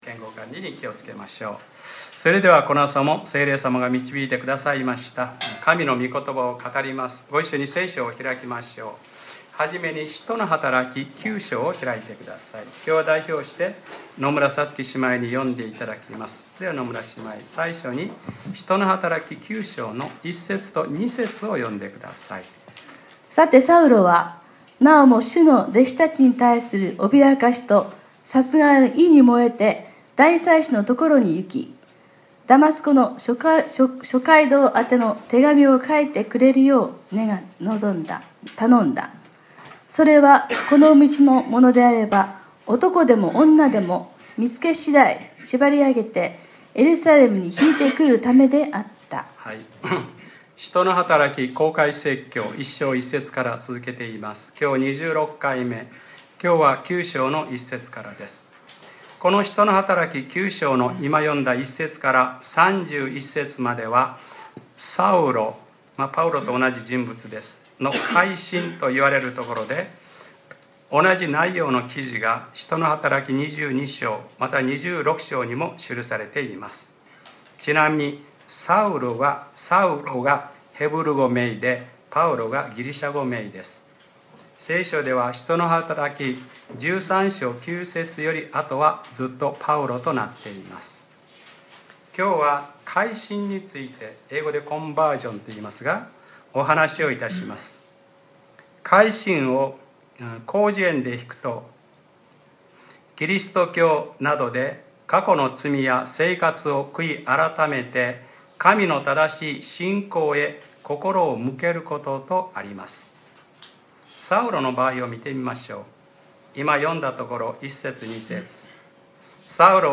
2018年08月05日（日）礼拝説教『使徒ｰ26：回心』